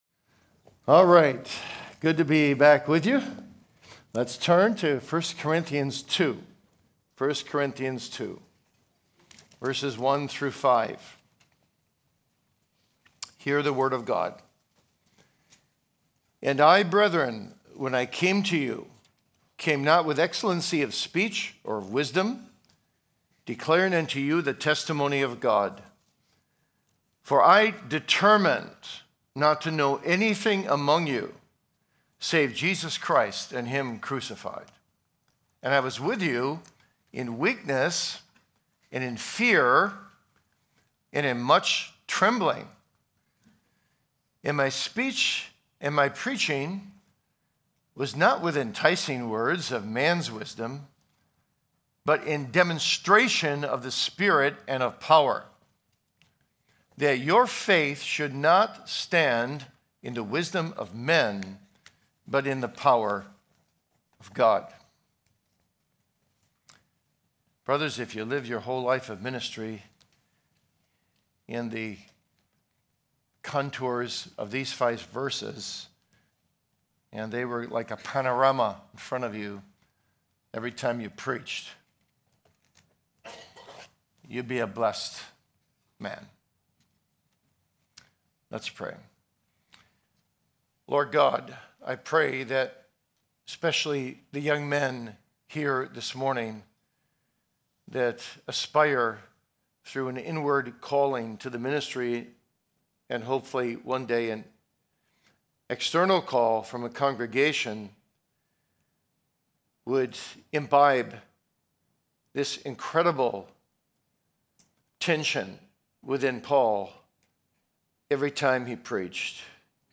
2026 E. Y. Mullins Lectures: The Experiential Aspect of Preaching